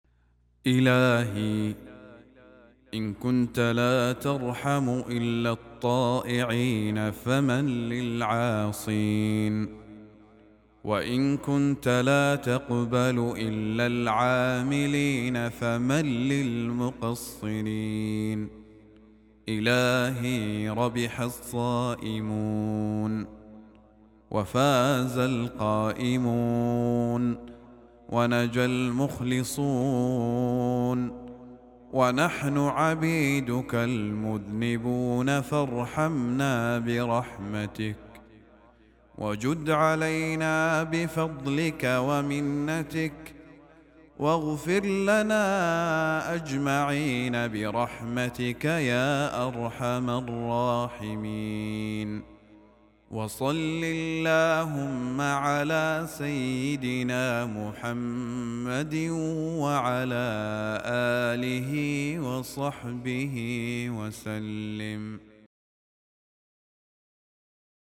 دعاء مؤثر يعبر عن الافتقار إلى رحمة الله وفضله، مع الاعتراف بالتقصير والذنب.